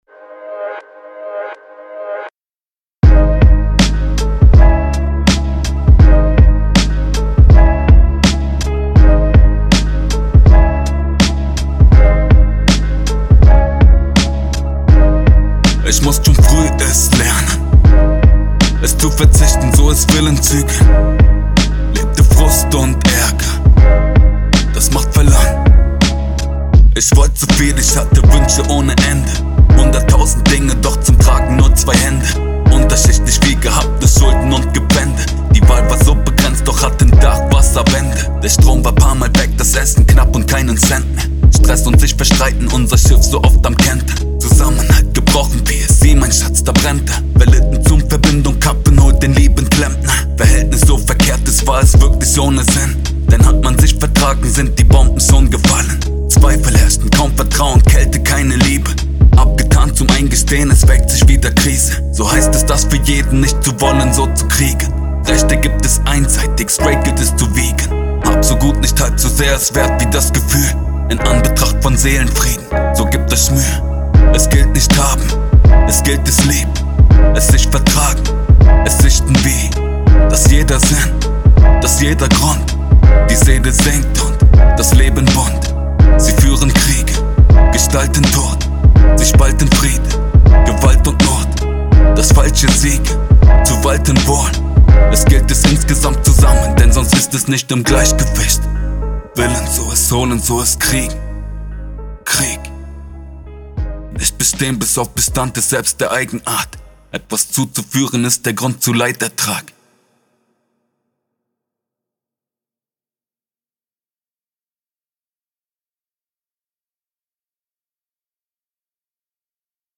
Hallo, ich mache jetzt schon seit vielen jahren musik im bereich hiphop/rap, jedoch habe ich mich mehr mit meinen rapskills auseinandergesetzt als abmischen...
Nu ja, eigentlich fehlt dem Ganzen was Glue. Tatsächlich sind die Vocal etwas dumpf und akustisch hinter dem Beat.
Dann wieder zusammengesetzt und mit etwas Ambience und Spice versehen.
Wobei der Beat ziemlich langweilig ist.